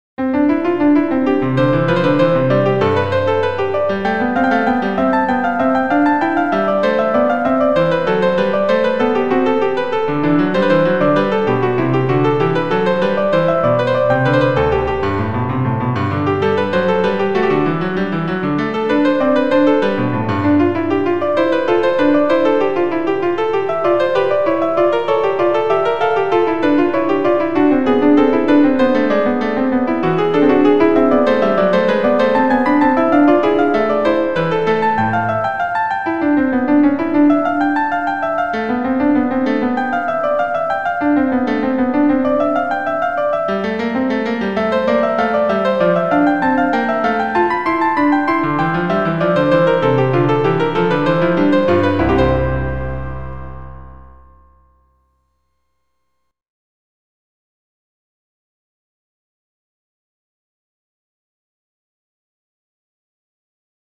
bach-invention-01-a4.mid.mp3